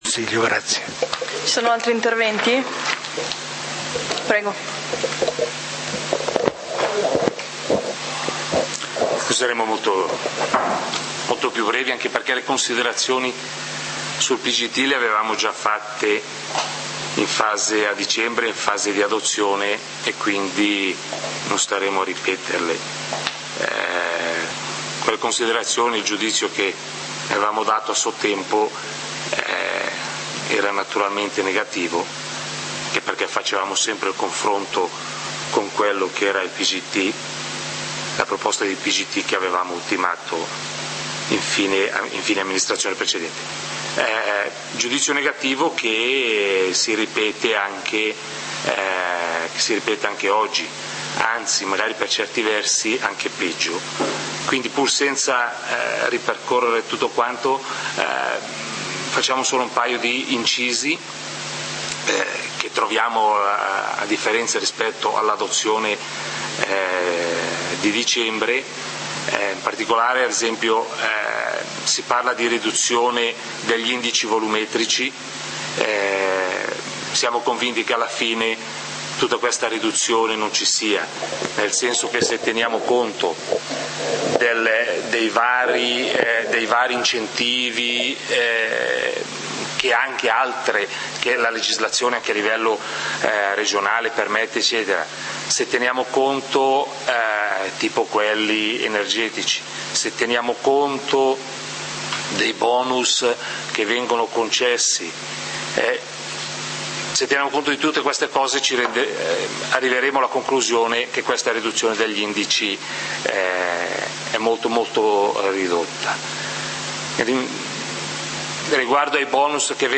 Consiglio comunale di Valdidentro del 30 Giugno 2014
Consiglio comunale del 30 Giugno 2014 torna alla lista dei punti Punto 3a: Piano di governo del territorio adottato con deliberazione di consiglio comunale n. 49 del 30.12.2013. Esame e decisione in merito alle osservazioni e ai pareri pervenuti. Approvazione definitiva; Intervento del consigliere Aldo Martinelli.